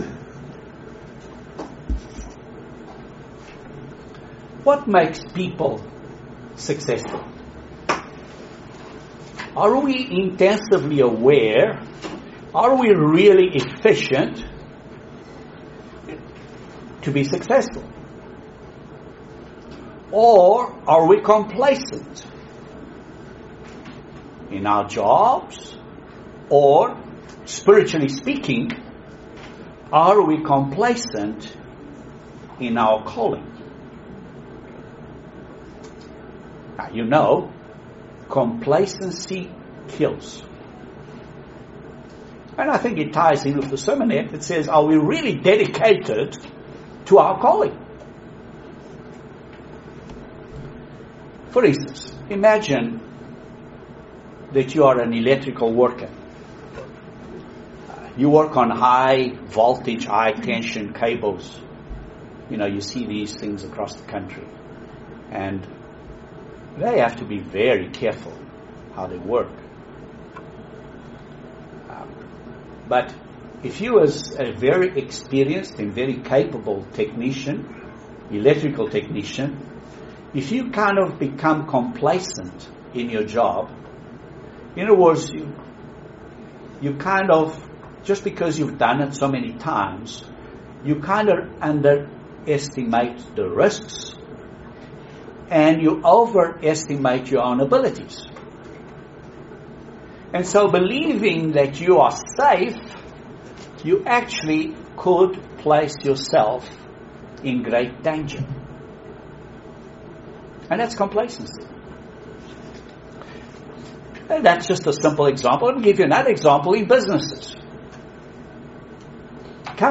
A Great Sermon on having no complacency in our calling.